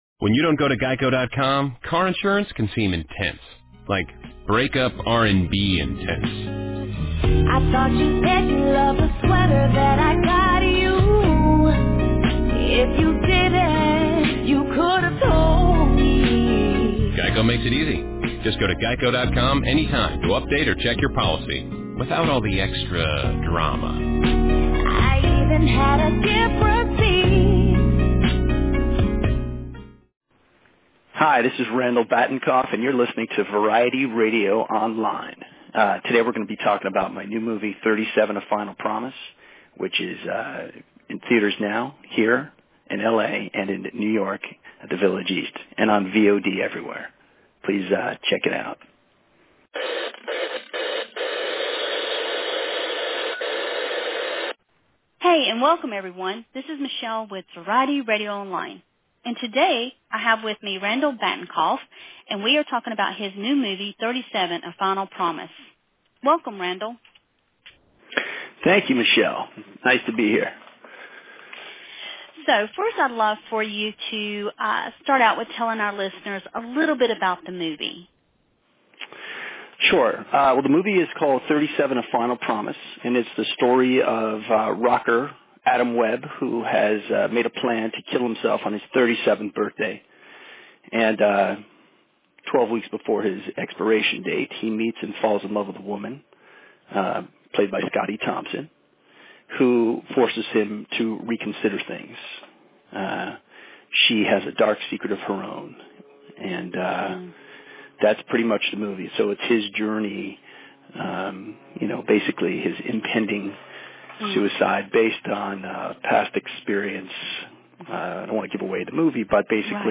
Interviews / Randall Batinkoff - "37: A Final Promise"
Randall Batinkoff calls into Variety Radio Online to discuss his film 37: A Final Promise.